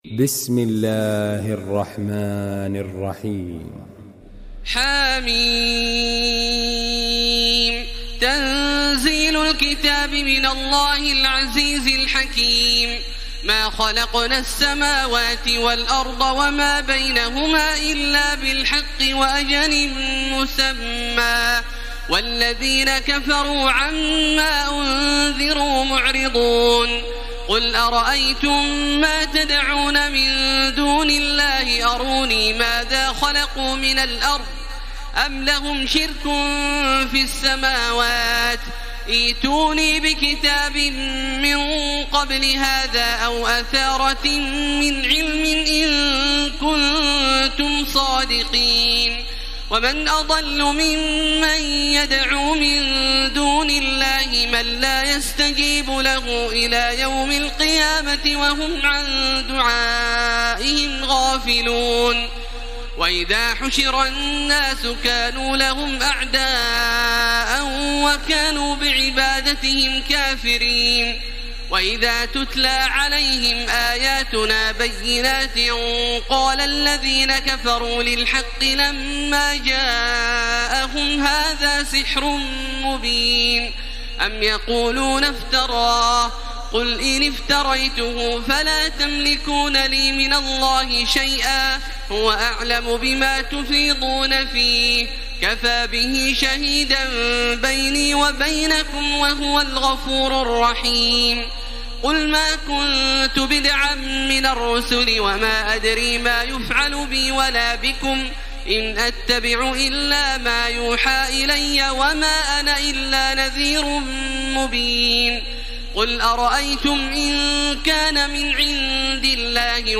تراويح ليلة 25 رمضان 1434هـ من سور الأحقاف و محمد والفتح (1-17) Taraweeh 25 st night Ramadan 1434H from Surah Al-Ahqaf and Muhammad and Al-Fath > تراويح الحرم المكي عام 1434 🕋 > التراويح - تلاوات الحرمين